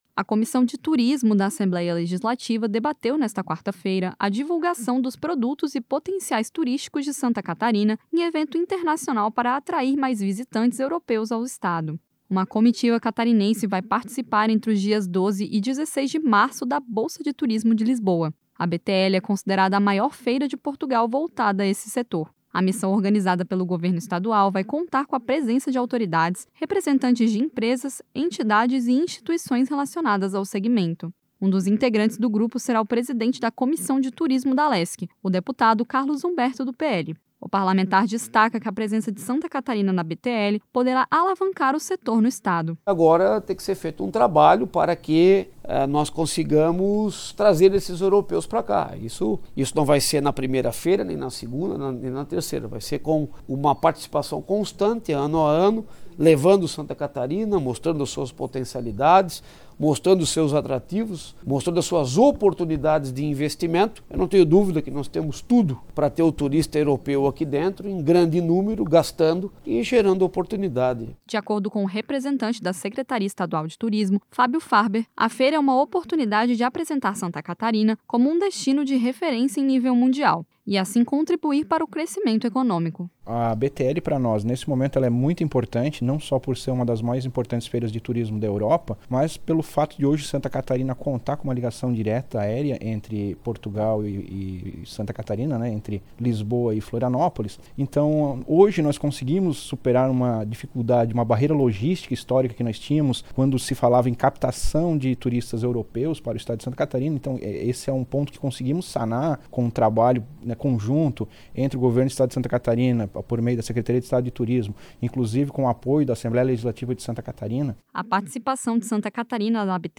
Entrevistas com:
- deputado Carlos Humberto (PL), presidente da Comissão de Turismo.